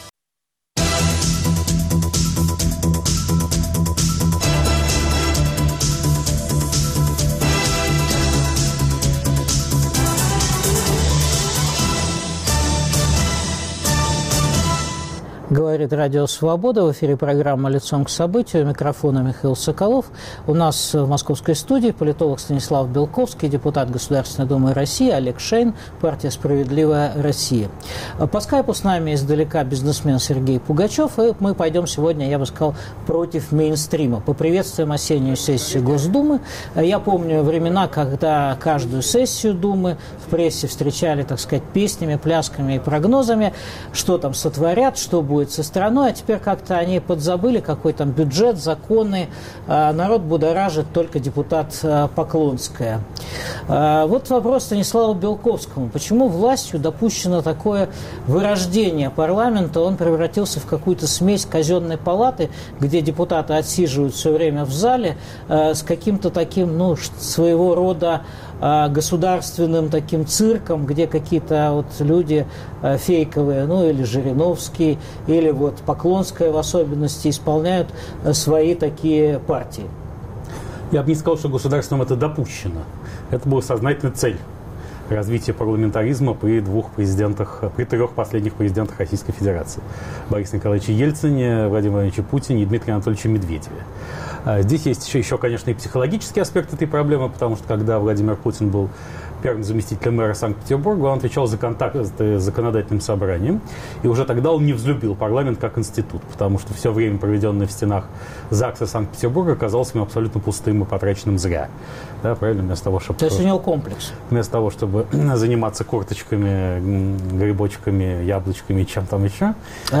Паралментаризм -2017 обсуждают Станислав Белковский, Олег Шеин, Сергей Пугачев.